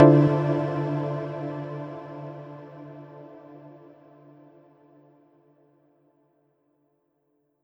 pause-retry-click.wav